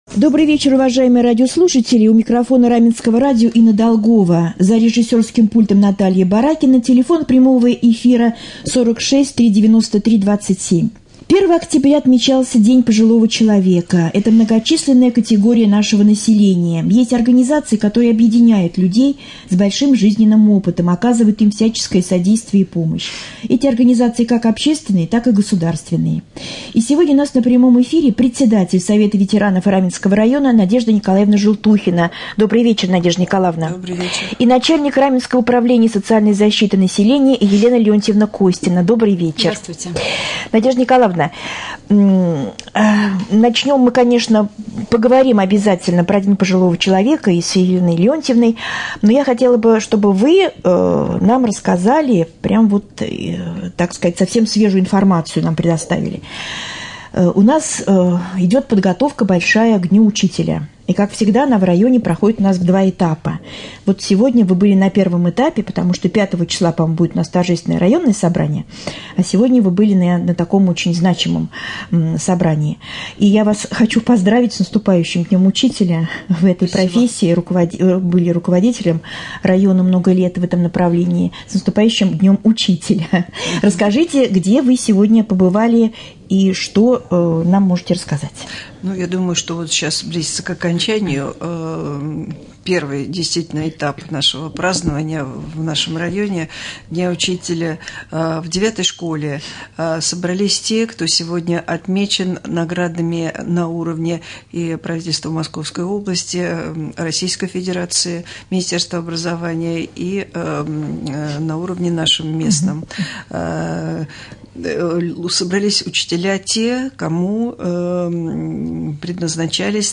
Гости студии